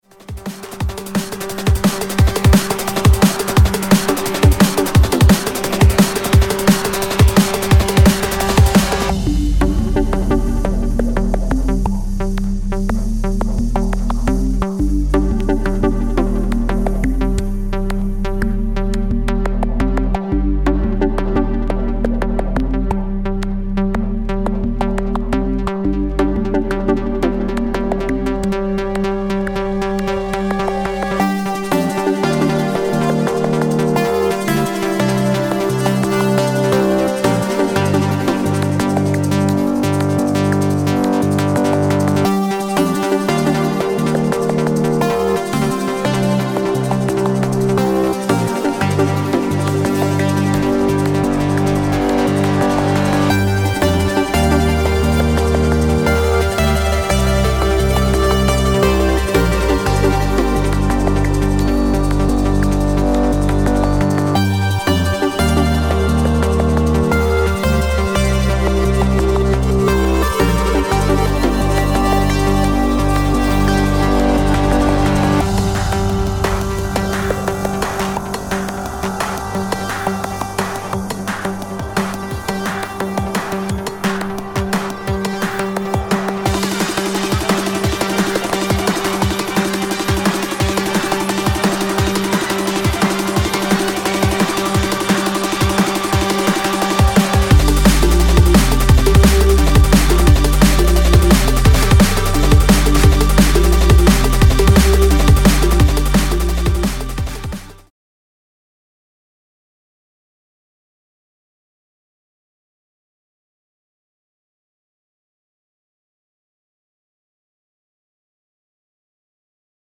Jungle/Drum n Bass